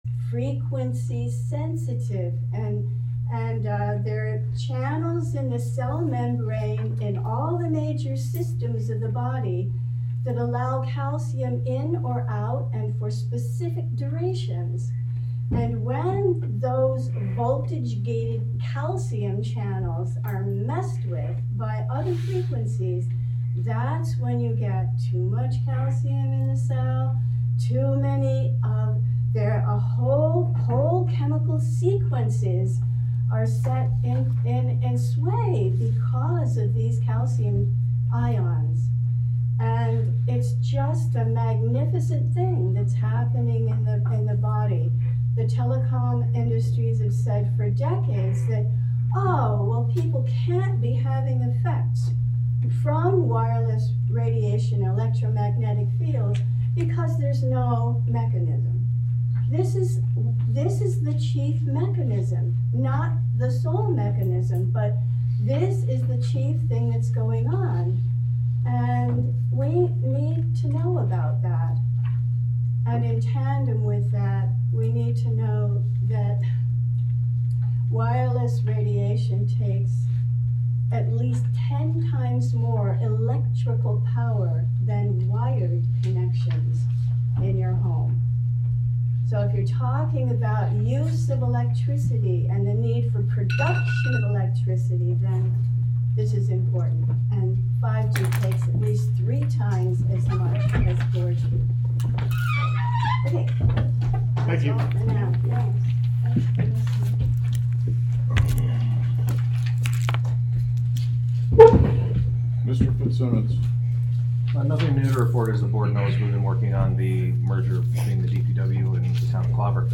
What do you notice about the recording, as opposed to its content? Live from the Village of Philmont: Village Board Meeting (Audio)